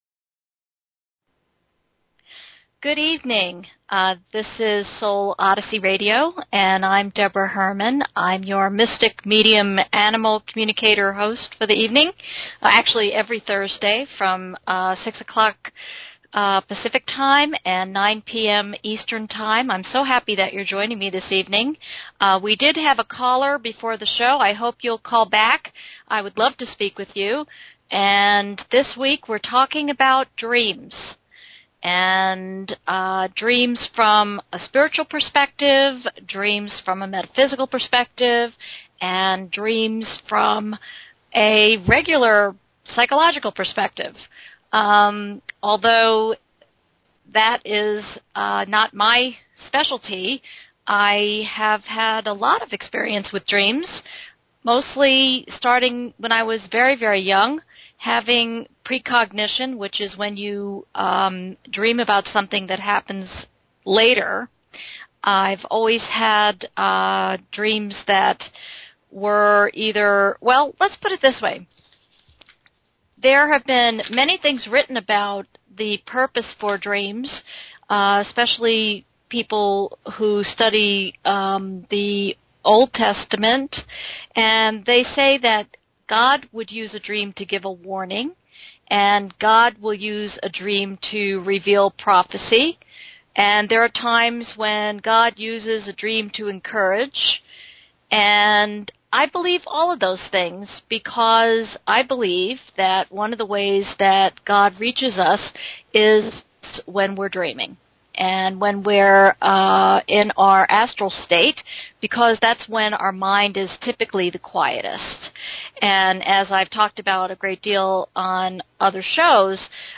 Talk Show Episode, Audio Podcast, Soul_Odyssey and Courtesy of BBS Radio on , show guests , about , categorized as